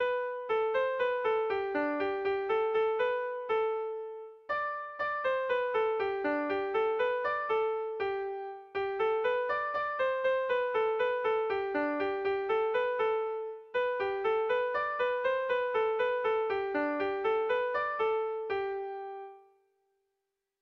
Bertso melodies - View details   To know more about this section
Erlijiozkoa
Ezpeleta < Lapurdi Garaia < Lapurdi < Basque Country